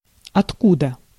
Ääntäminen
IPA : /ʍɛns/ IPA : /wɛns/